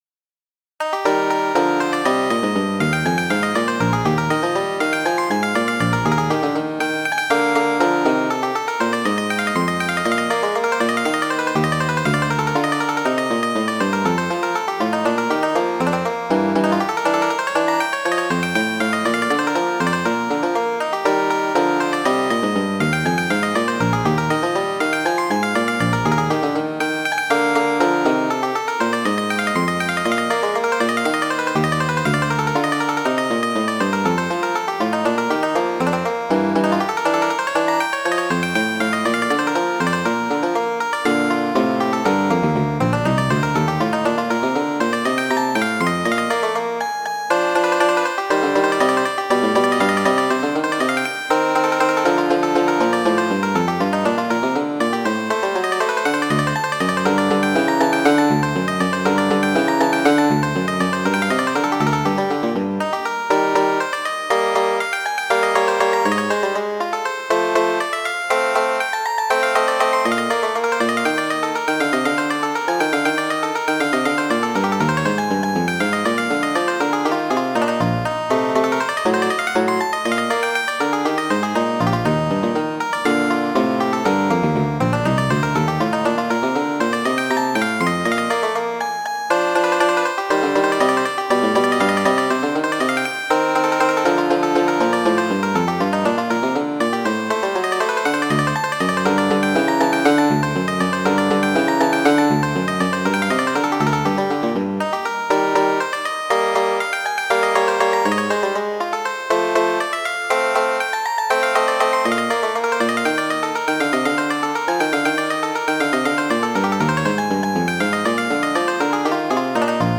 | Kajero 32 ª | En PDF (paĝo 42ª) | Kajeroj | Muziko : Marŝo , nº 19 de la Albumo por Ana Magdalena, verkita de J. S. Bach.